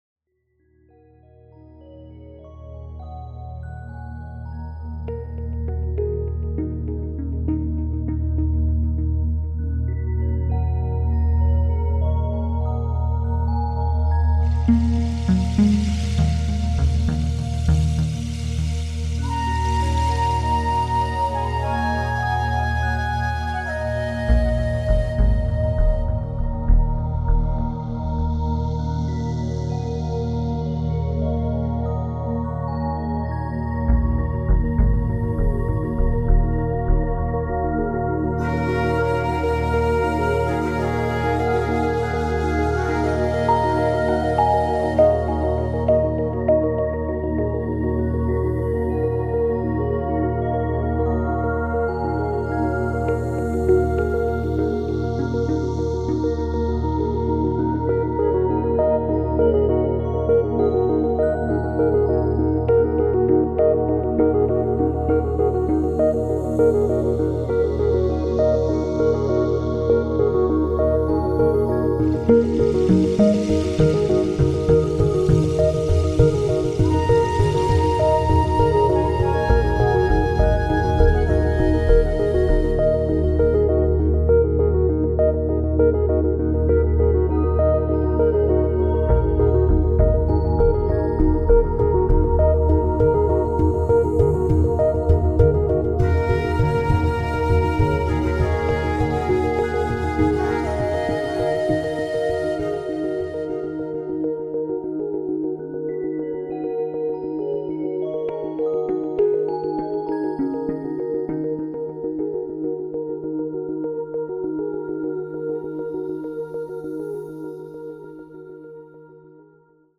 Une ambience de plénitude totale, très aérien.